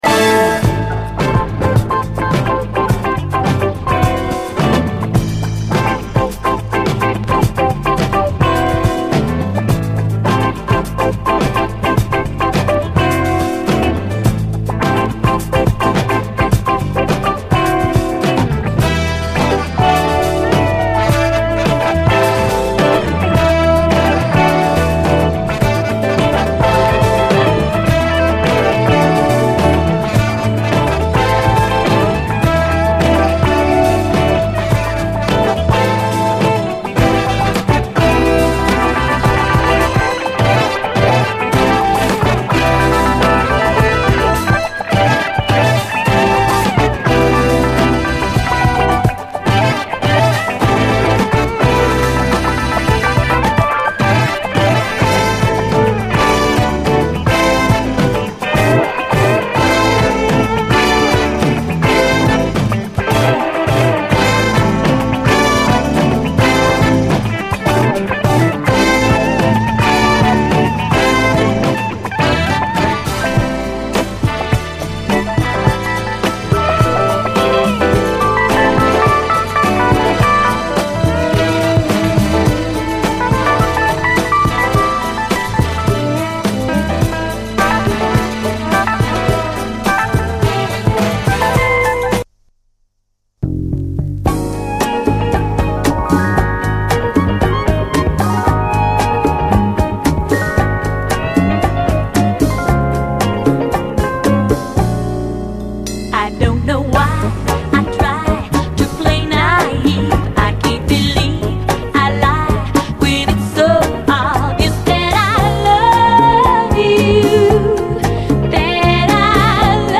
SOUL, 70's～ SOUL, DISCO
インスト・ジャズ・ファンク
涼しげなメロウ・ブラジリアン・ソウル〜AOR調
ディスコ〜ファンクも意識しつつグルーヴィーで好内容な76年作！